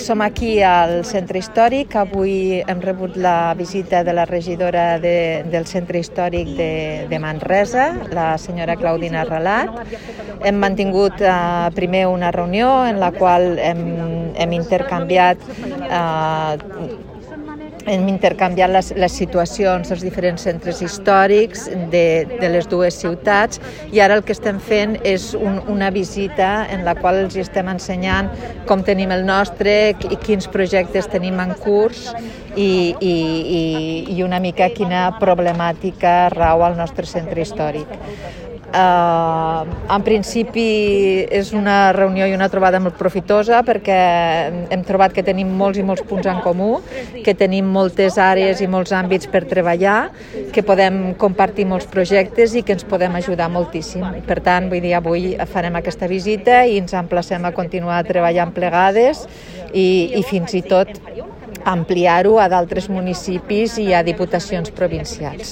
Tall de veu de la tinent d'alcalde Montse Pifarré
tall-de-veu-de-la-tinent-dalcalde-montse-pifarre